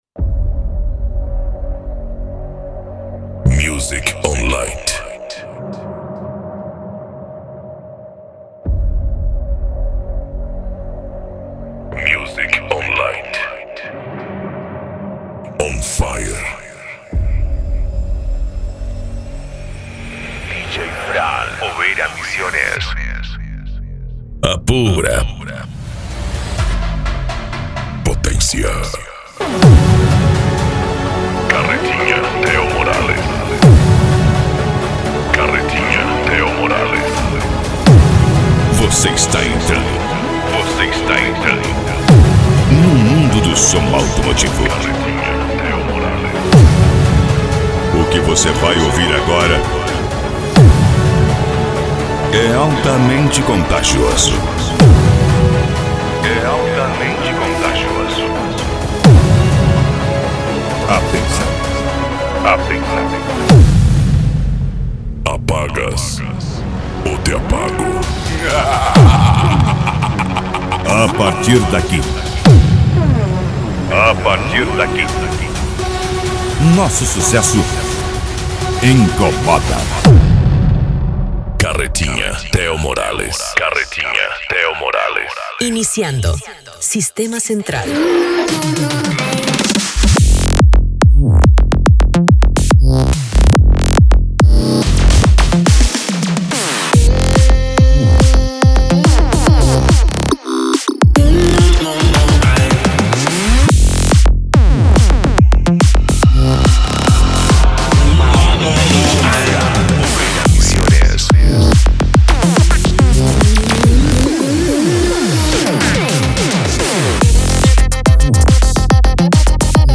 Psy Trance
Remix